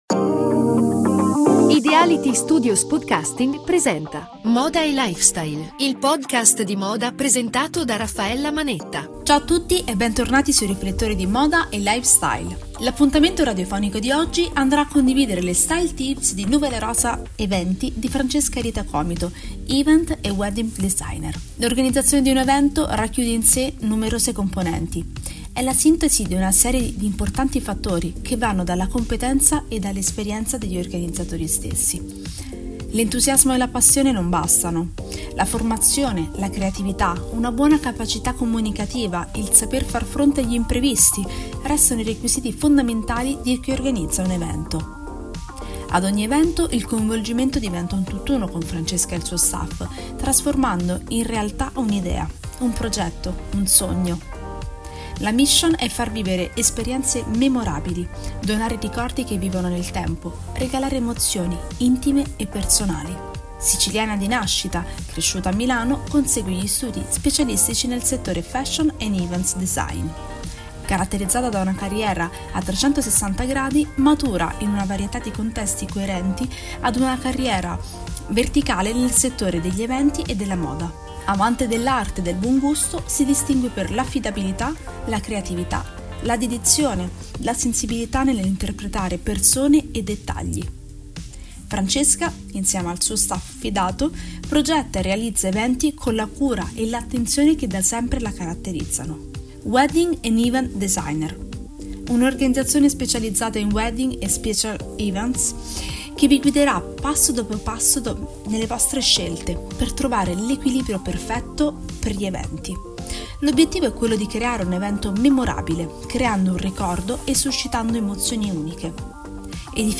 intervista
L’appuntamento radiofonico di oggi andrà a condividere le Style tips di Nuvole Rosa Eventi